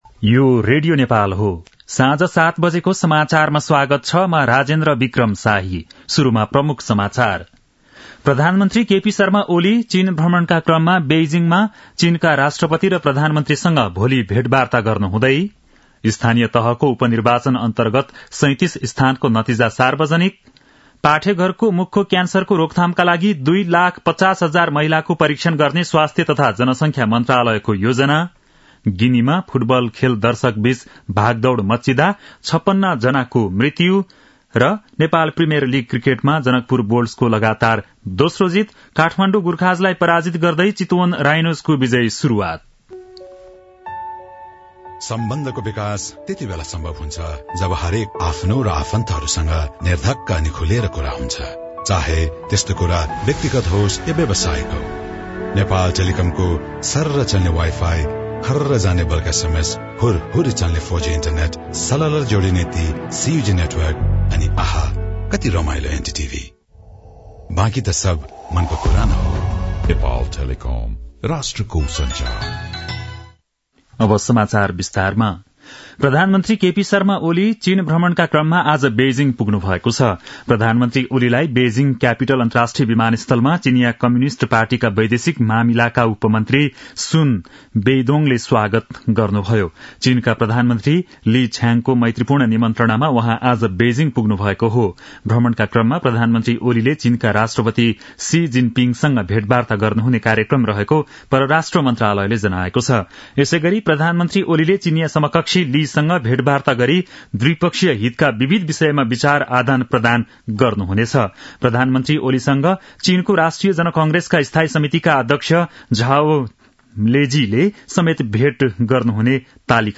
बेलुकी ७ बजेको नेपाली समाचार : १८ मंसिर , २०८१